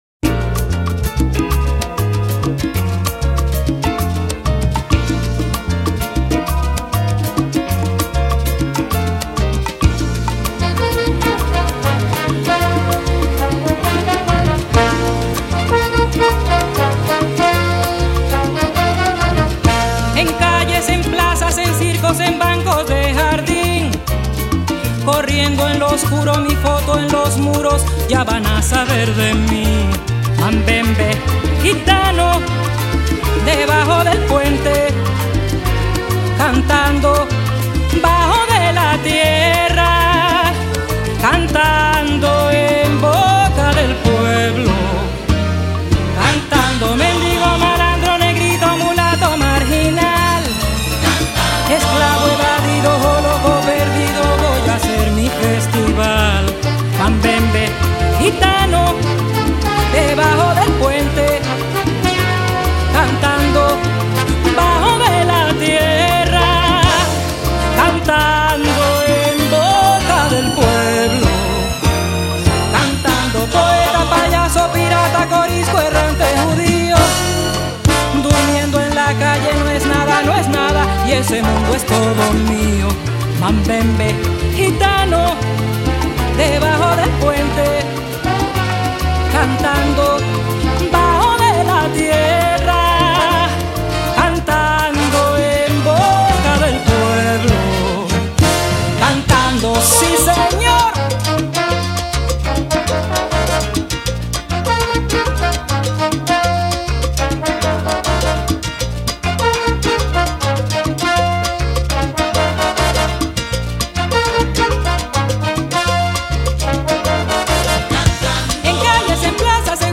It is a very sweet song; I’ve always loved it.
1977  Genre: Latin   Artist